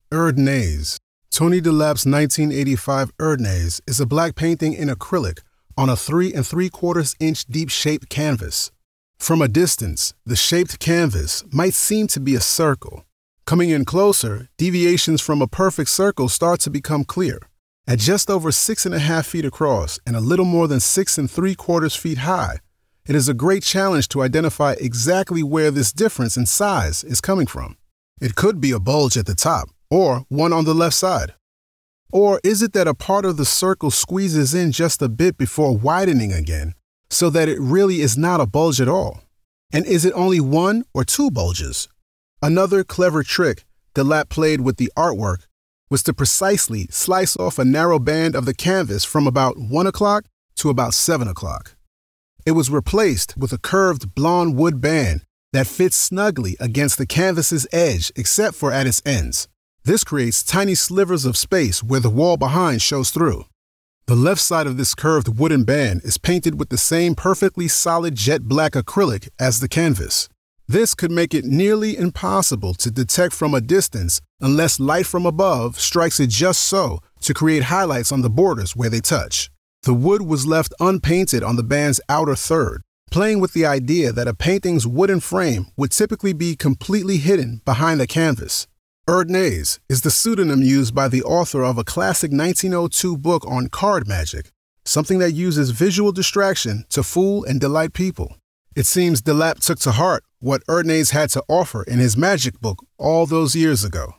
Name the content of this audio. Audio Description (01:44)